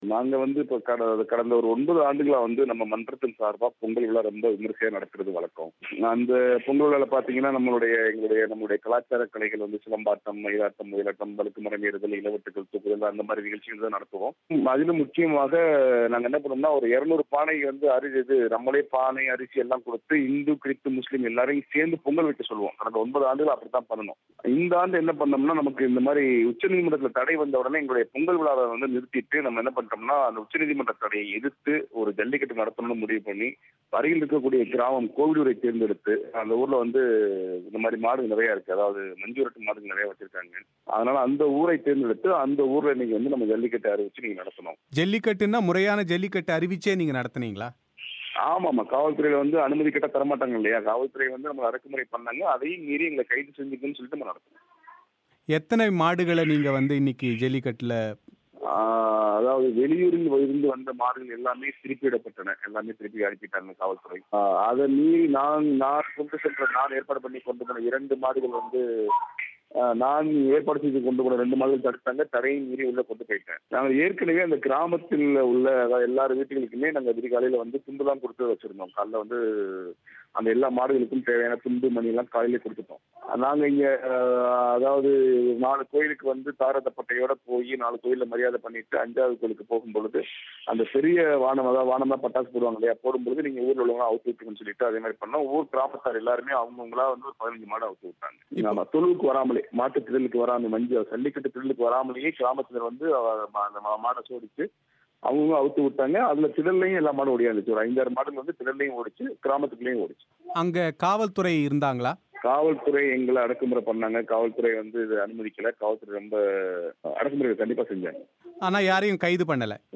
பிபிசி தமிழோசைக்கு அளித்த செவ்வியை நேயர்கள் இங்கே கேட்கலாம்.